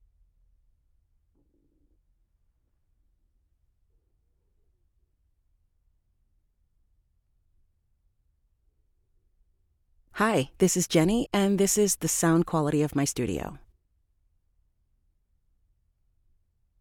Female
Approachable, Confident, Conversational, Reassuring, Warm
US general American
Her voice has been described as professional, rich, warm, and confident with elegant inviting tones.
Microphone: Neumann TLM 102, Sennheiser 416
Audio equipment: Custom made Whisperroom, iMAC, Audient iD4